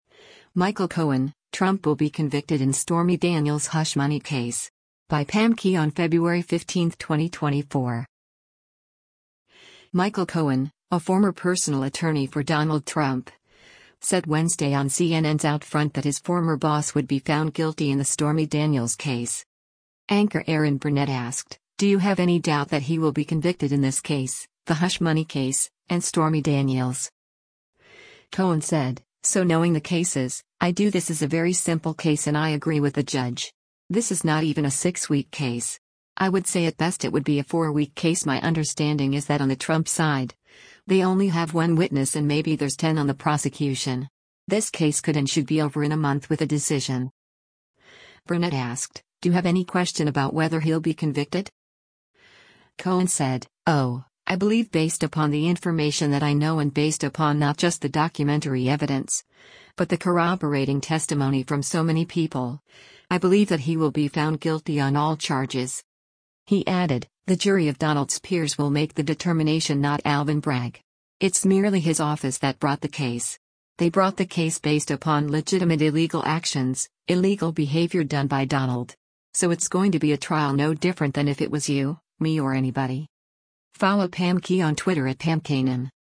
Michael Cohen, a former personal attorney for Donald Trump, said Wednesday on CNN’s “OutFront” that his former boss would be found guilty in the Stormy Daniels case.